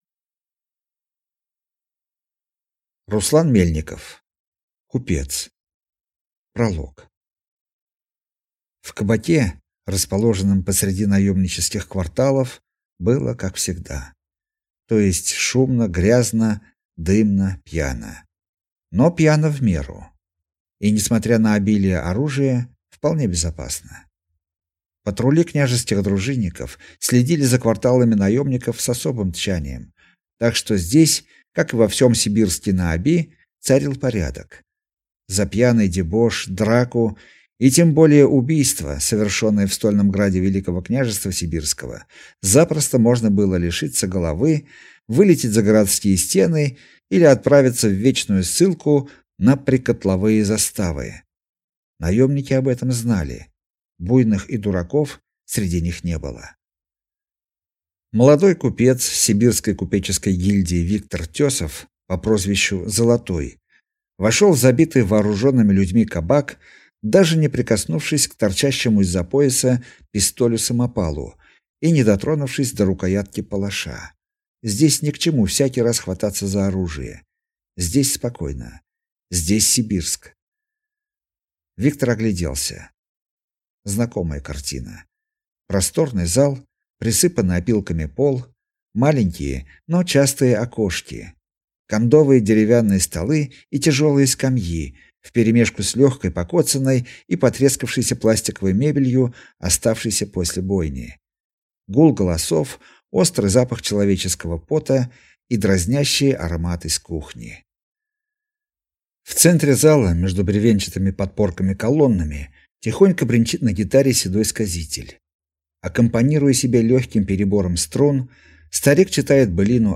Аудиокнига Купец | Библиотека аудиокниг